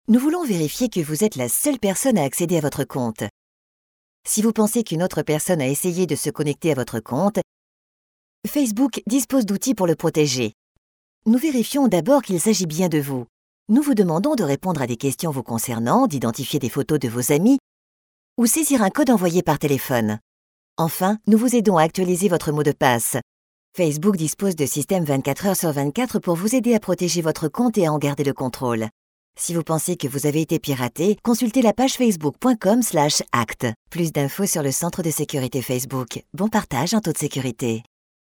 Tutoriels